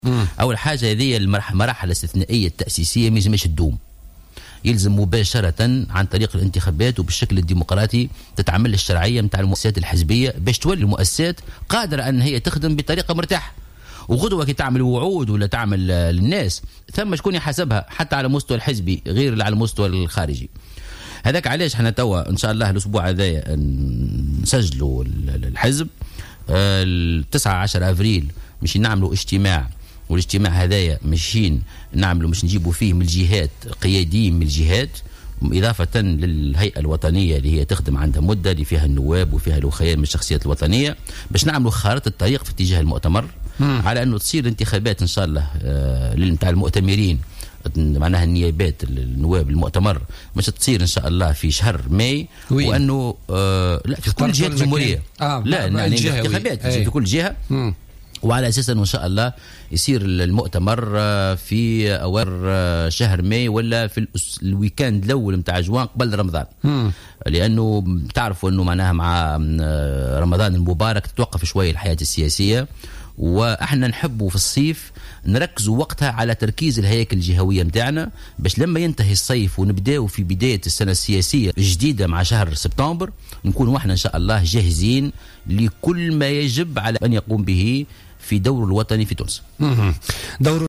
أعلن محسن مرزوق المنسق العام لحركة مشروع تونس وضيف برنامج بوليتكا لليوم الأربعاء 23 مارس 2016 أن أول مؤتمر انتخابي للحركة سيكون في شهر ماي المقبل أو خلال الأسبوع الأول من شهر جوان وفق قوله.